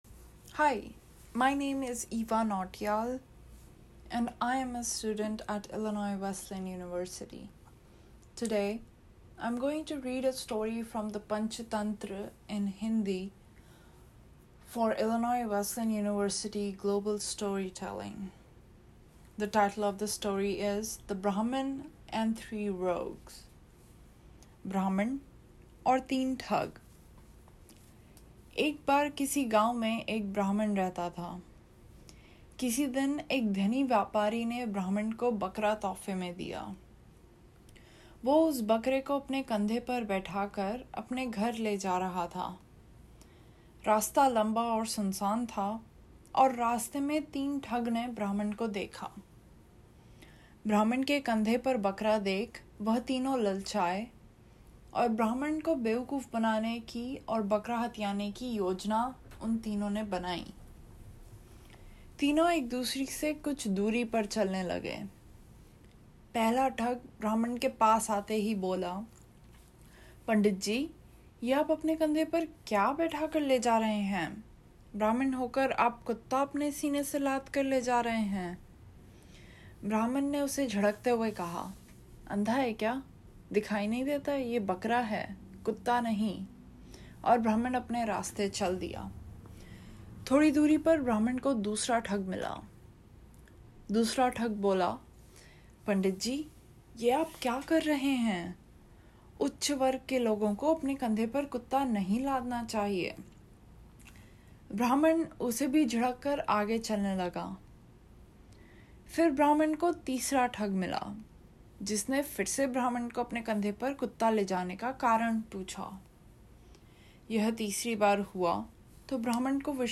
Oral History Item Type Metadata